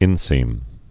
(ĭnsēm)